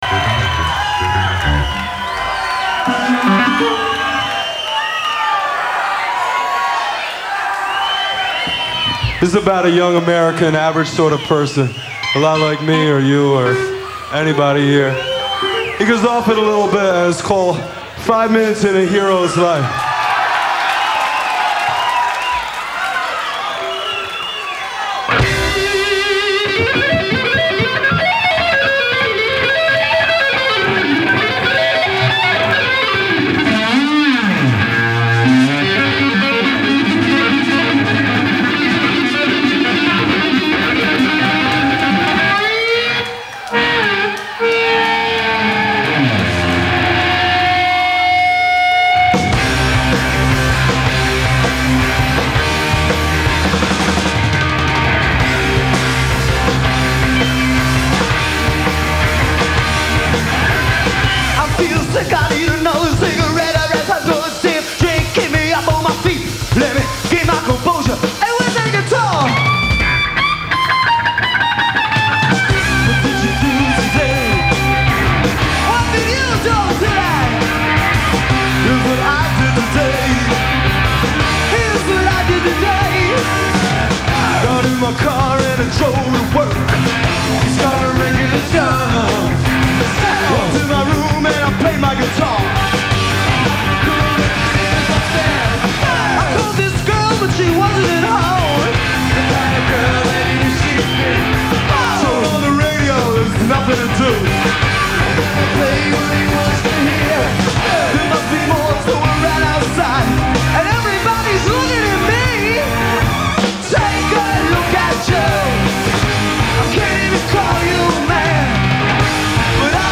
lead vocals
guitar
keyboards
bass